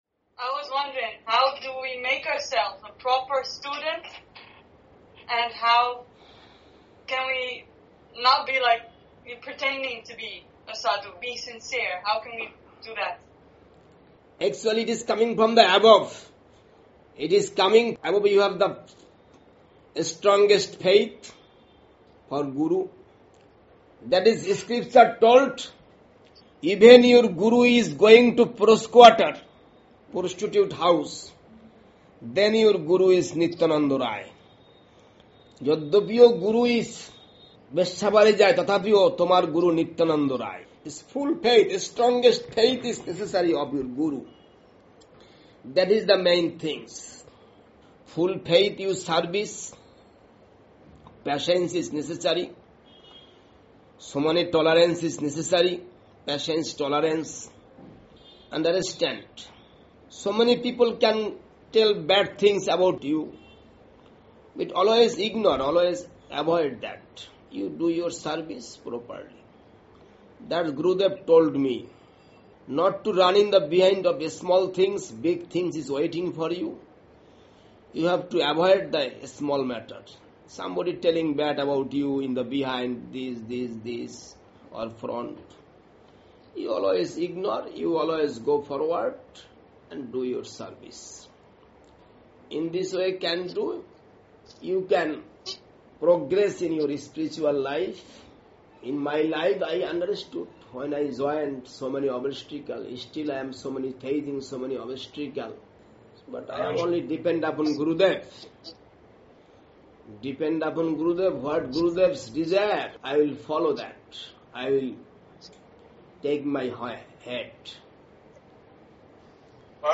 Speaking to the devotees in London